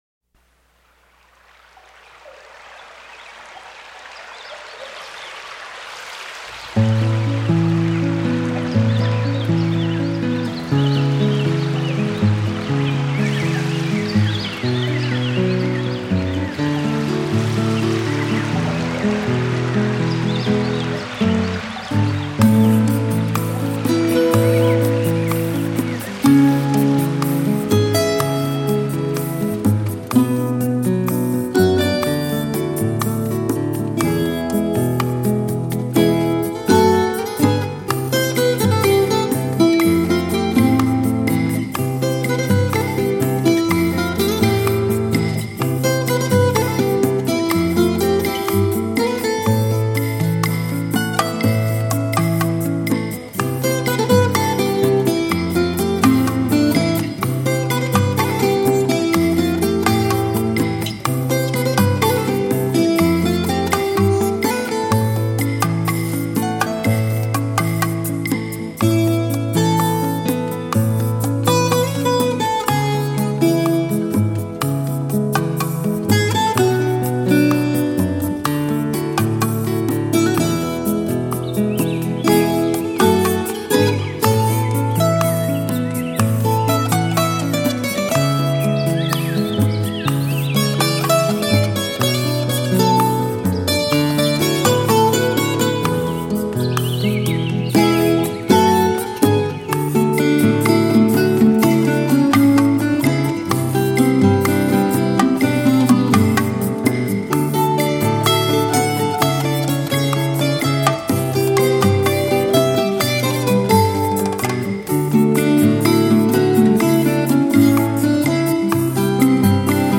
warm, enveloping sounds
Puerto Rican cuatro music